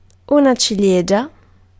Ääntäminen
UK : IPA : /ˈt͡ʃɛɹi/ US : IPA : /ˈt͡ʃɛɹi/